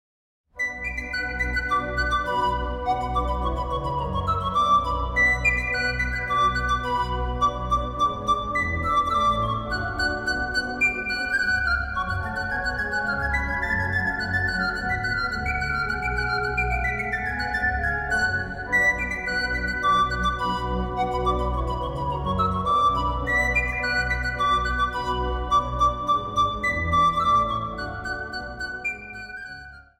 Instrumentaal | Panfluit